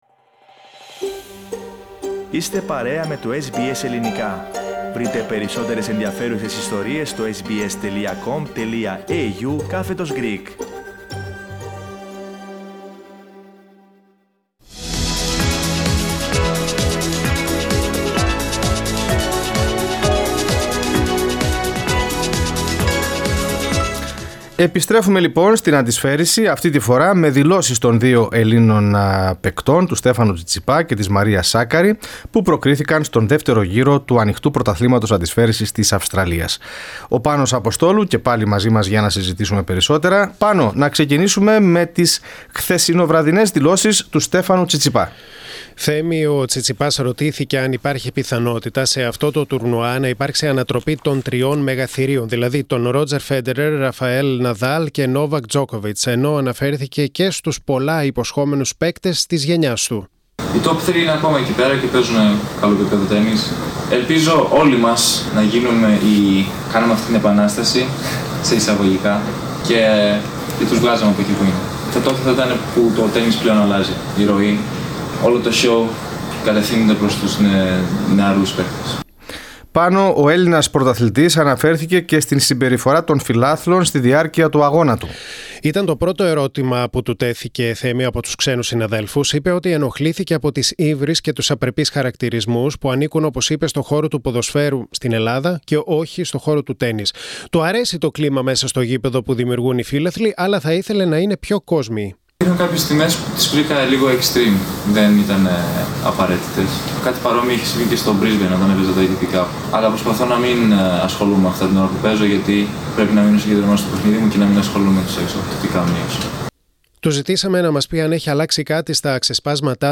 Σε πολλά απάντησε ο Στέφανος Τσιτσιπάς στην πρώτη του συνέντευξη για το Australian Open: για τους παίκτες της γενιάς του και τους Φέντερερ, Ναδάλ, Τζόκοβιτς, για την συμπεριφορά των Ελλήνων φιλάθλων και για τη σχέση του με τον πατέρα και προπονητή του, Απόστολο Τσιτσιπά.